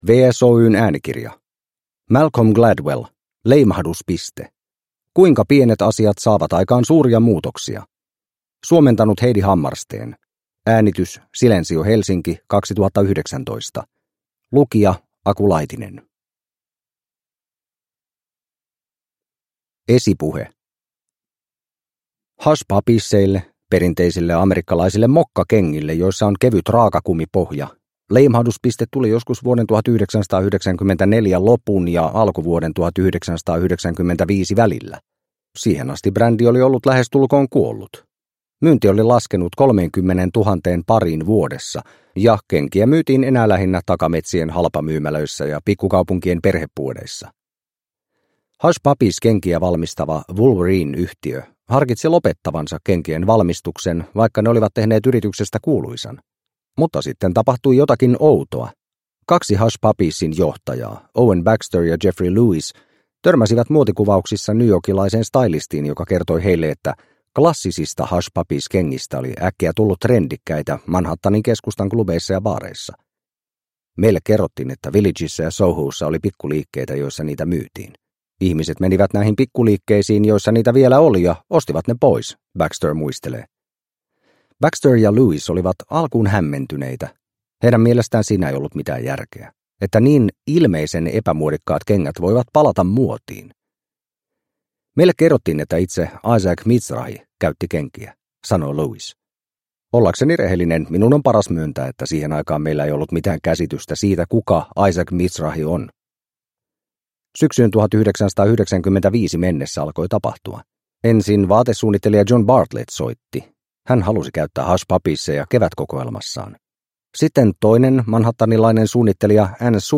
Leimahduspiste – Ljudbok – Laddas ner